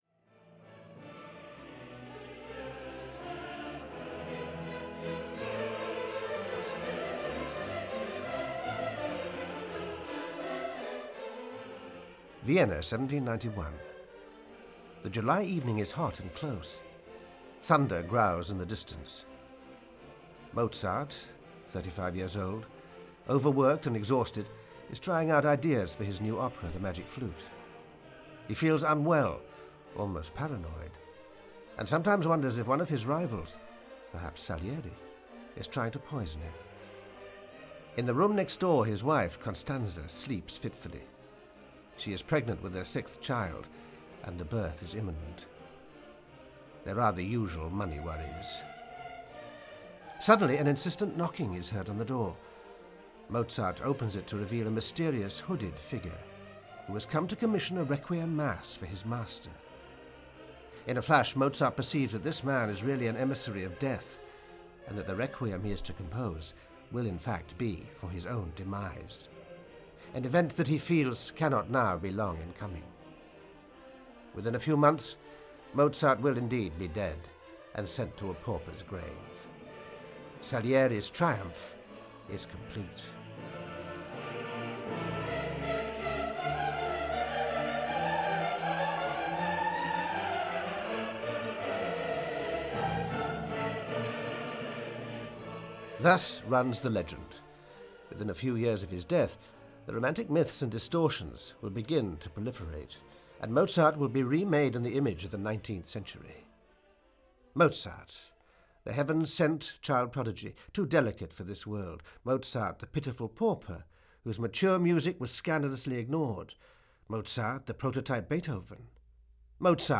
Ukázka z knihy
More than 70 excerpts from the symphonies, operas, concertos and chamber music of Mozart.